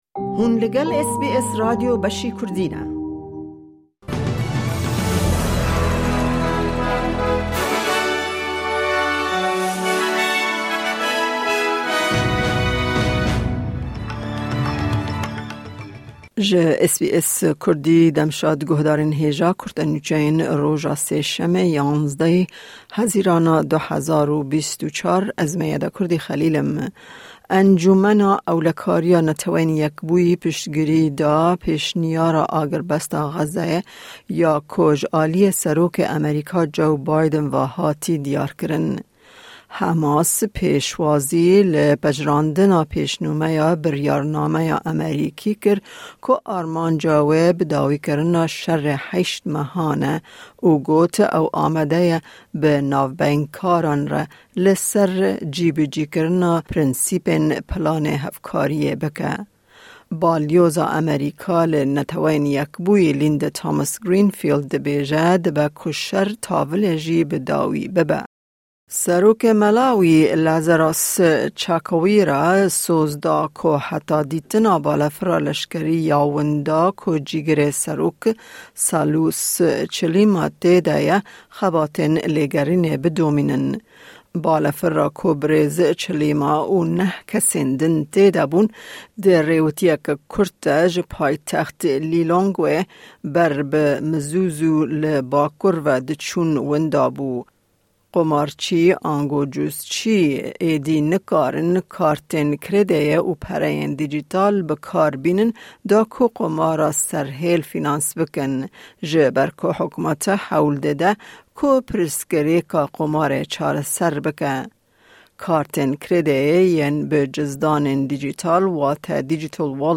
Kurte Nûçeyên roja Sêşemê 11î Hezîrana 2024